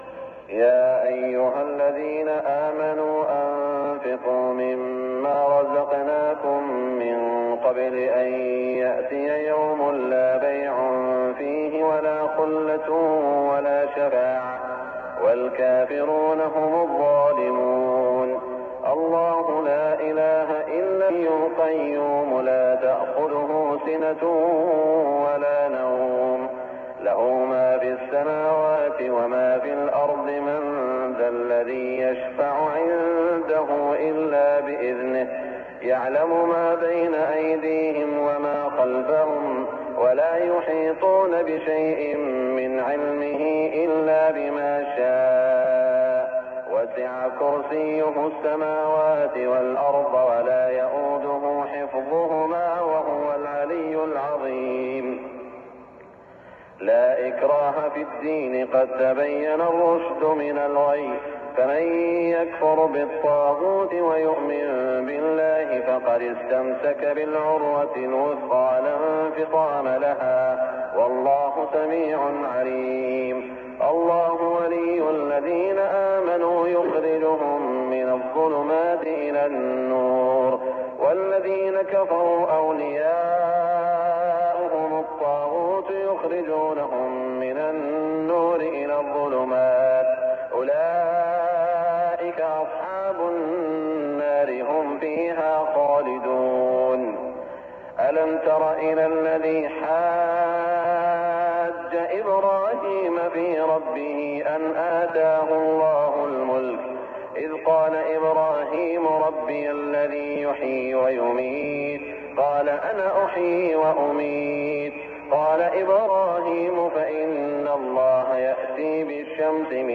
صلاة الفجر 1418هـ من سورة البقرة > 1418 🕋 > الفروض - تلاوات الحرمين